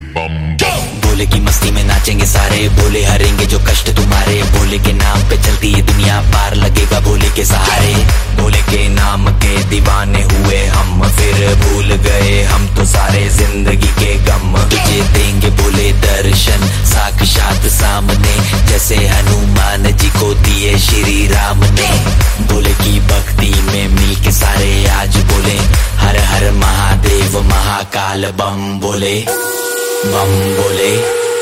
Category Bollywood